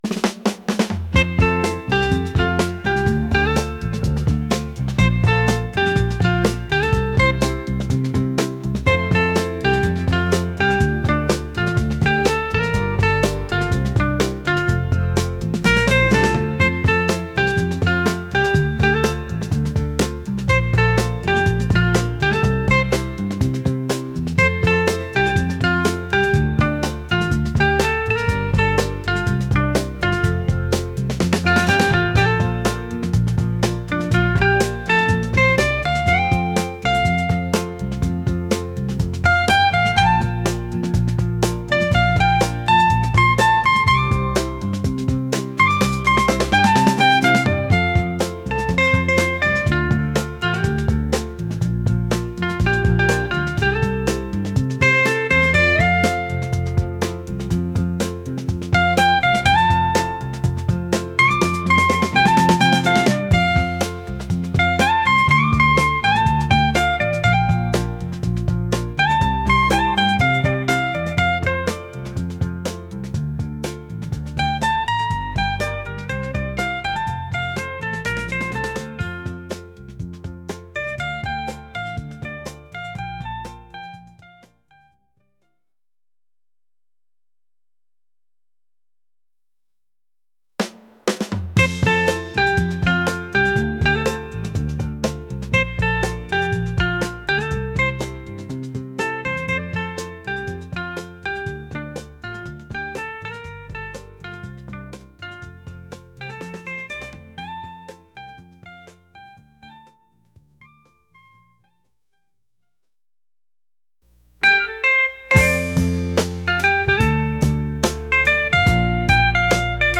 upbeat | soul